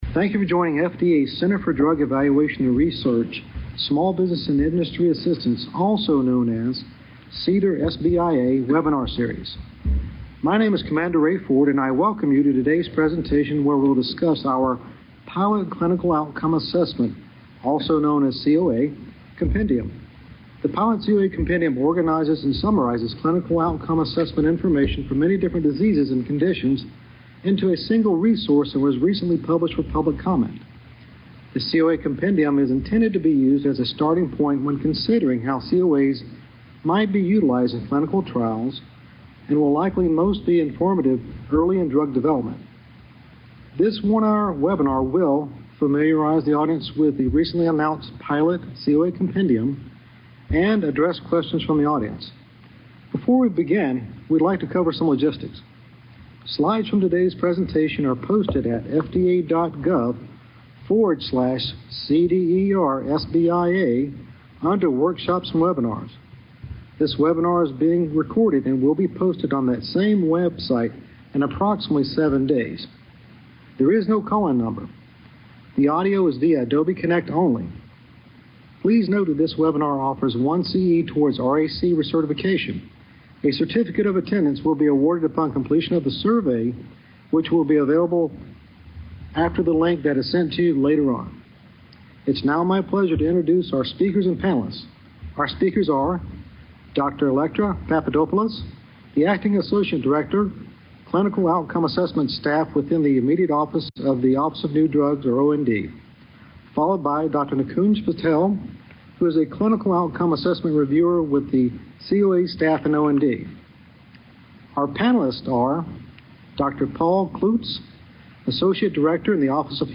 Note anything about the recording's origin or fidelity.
This one hour webinar: Familiarized the audience with the recently announced pilot COA Compendium. Addressed questions from the audience.